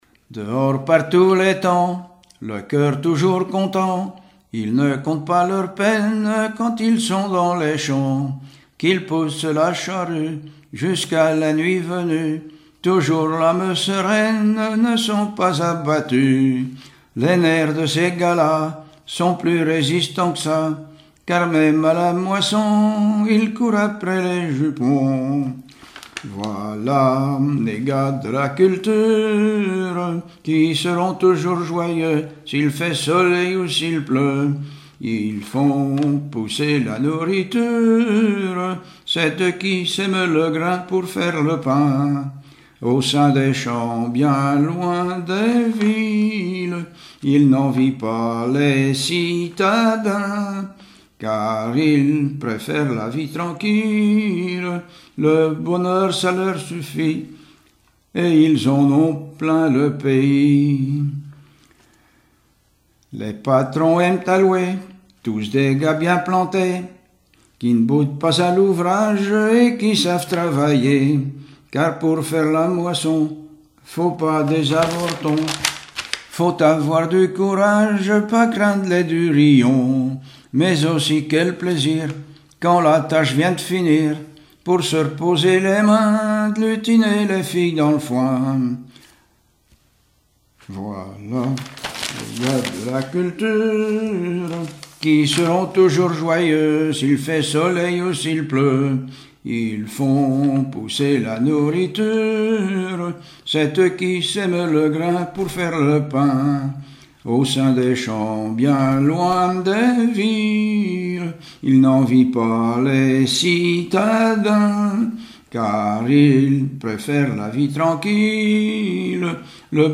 sur un timbre
Témoignages et chansons
Pièce musicale inédite